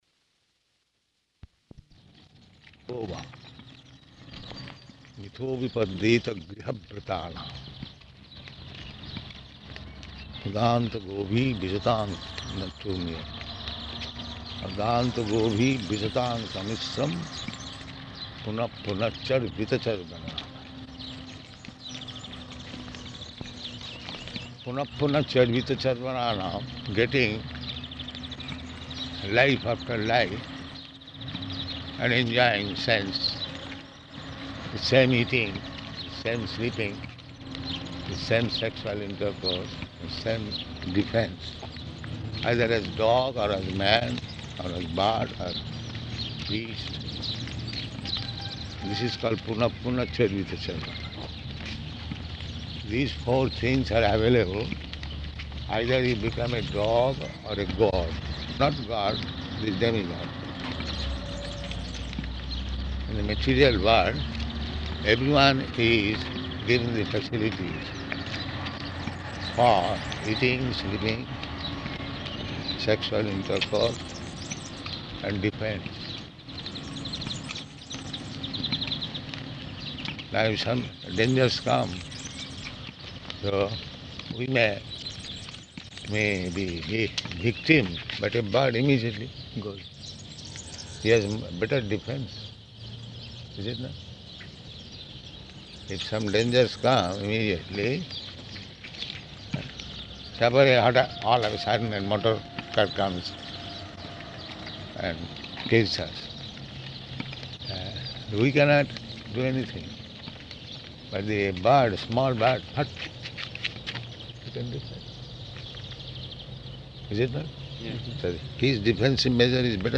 Morning Walk --:-- --:-- Type: Walk Dated: May 30th 1974 Location: Rome Audio file: 740530MW.ROM.mp3 Prabhupāda: Mitho 'bhipadyeta gṛha-vratānām.